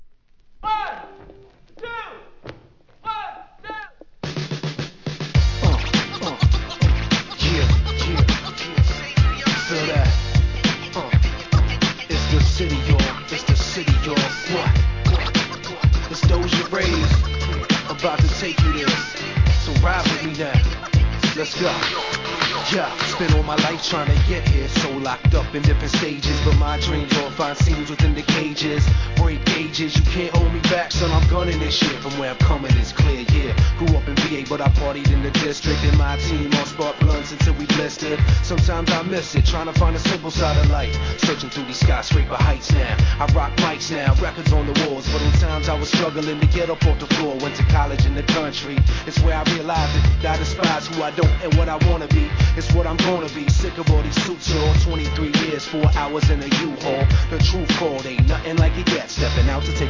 HIP HOP/R&B
ソウルフルなネタ使いのフロアキラー！！